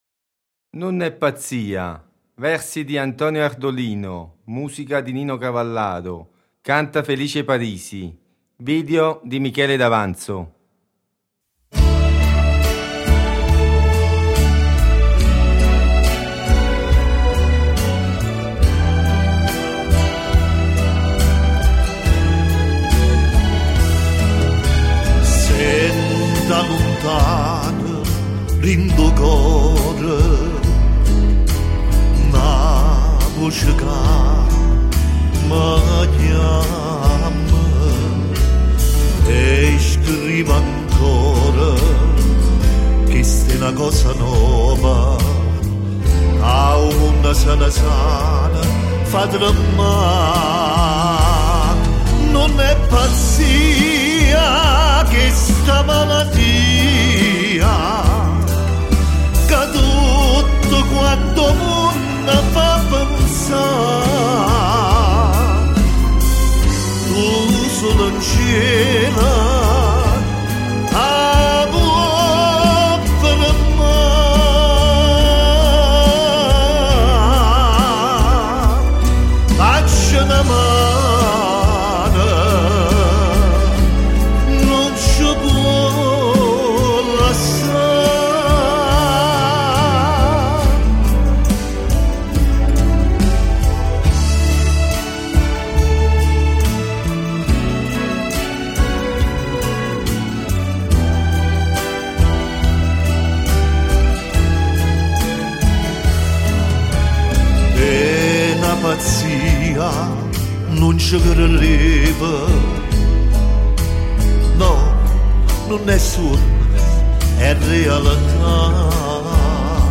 interpretato dal cantante nolano